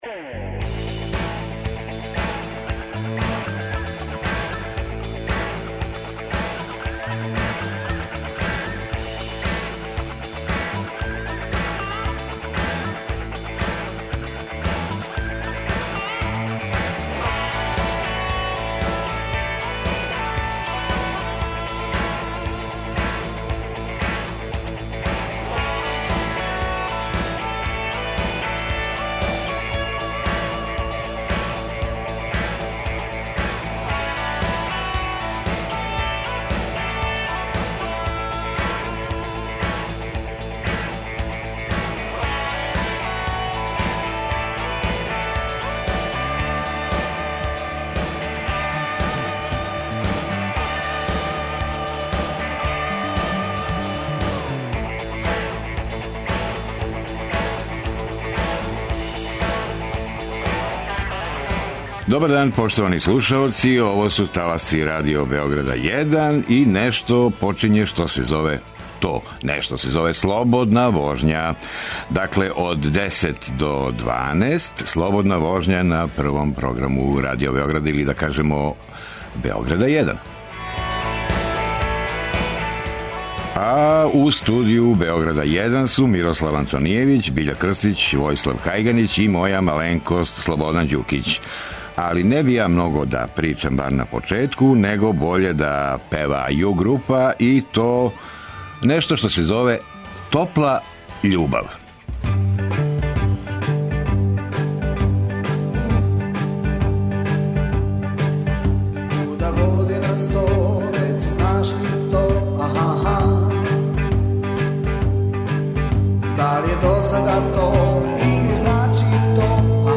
Током септембра слушаћете нашу емисију колажног типа.